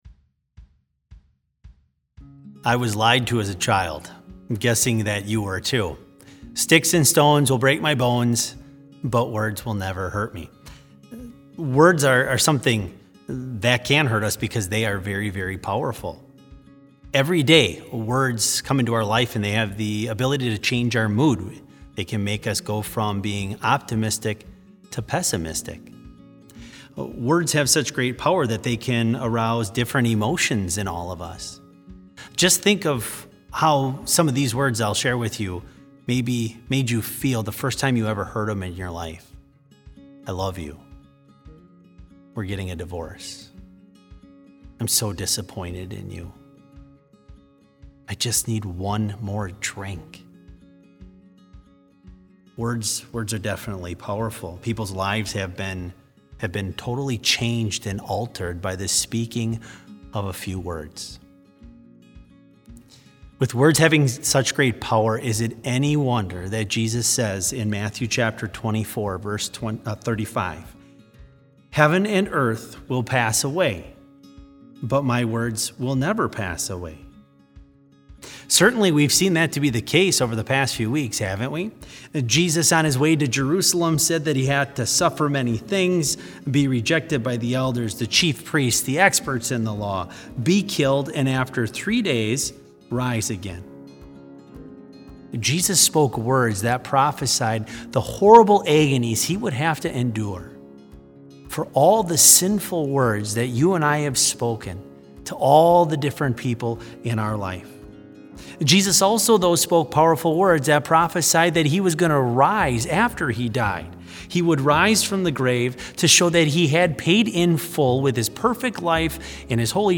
Special service held on April 21, 2020, in Trinity Chapel
Complete service audio for BLC Devotion - April 21, 2020